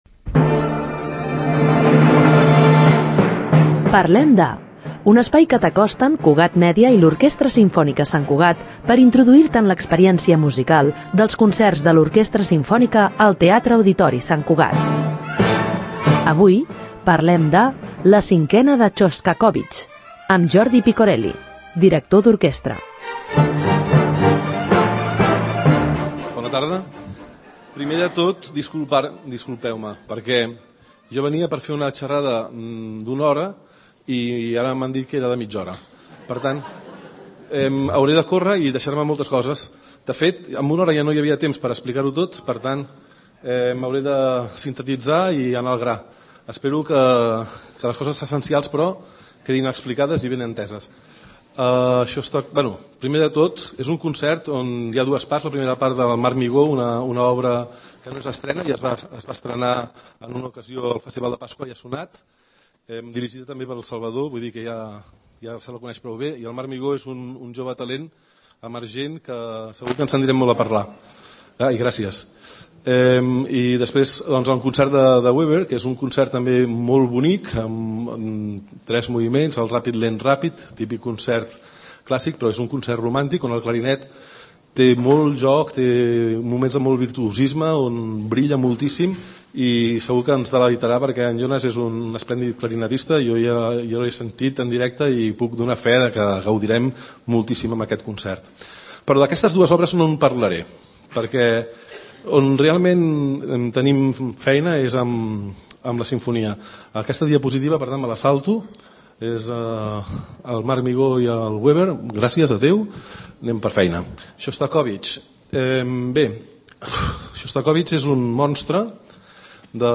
Introdueix-te en l’experiència musical dels concerts de l’Orquestra Simfònica Sant Cugat (OSSC) al Teatre-Auditori a través de les conferències amb persones expertes que organitza l’OSSC.